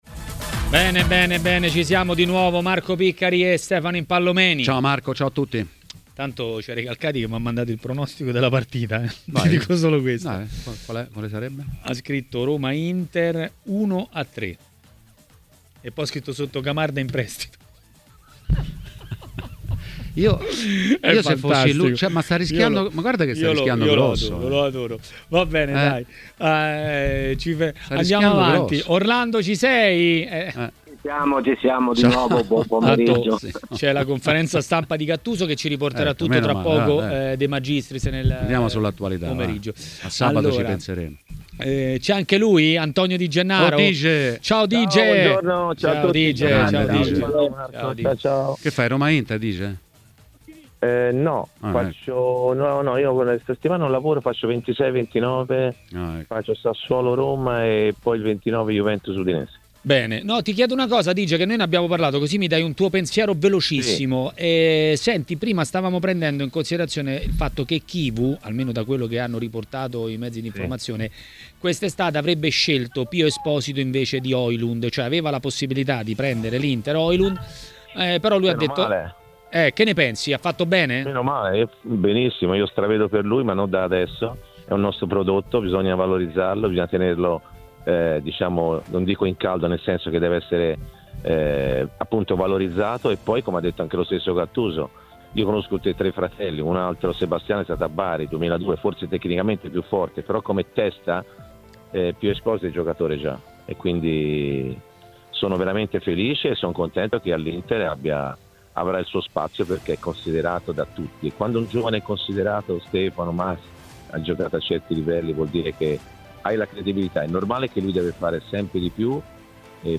L'ex calciatore e commentatore tv Antonio Di Gennaro è intervenuto a TMW Radio, durante Maracanà.